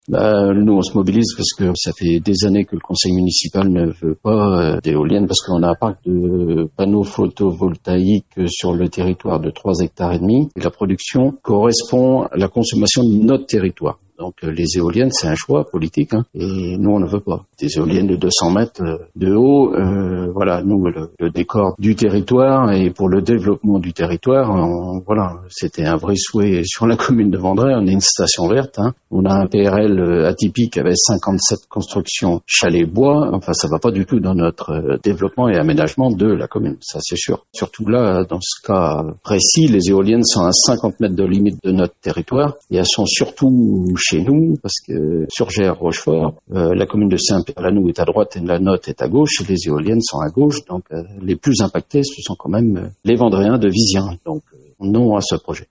On écoute Pascal Tardy, le maire de La Devise :